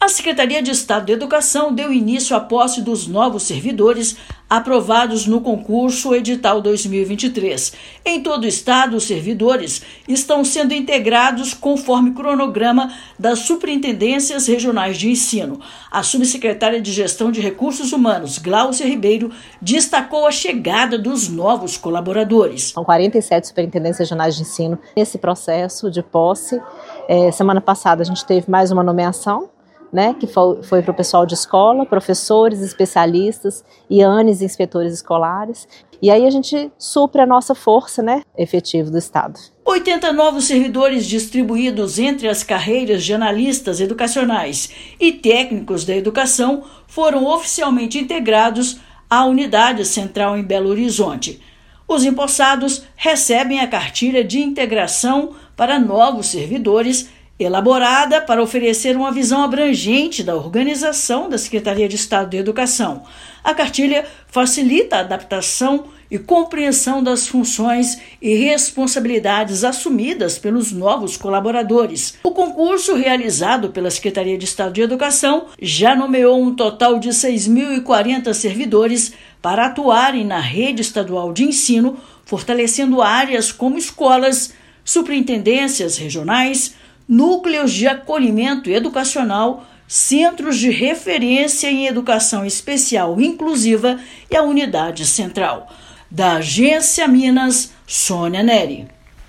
Nomeados estão tomando posse em todo o estado, de acordo com cronograma de cada Superintendência Regional de Ensino. Ouça matéria de rádio.